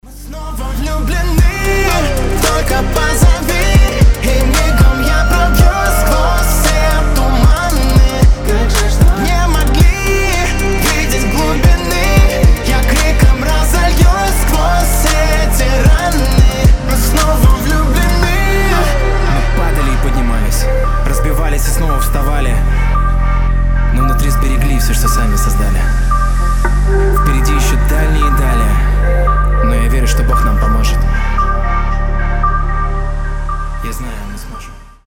мужской голос
красивые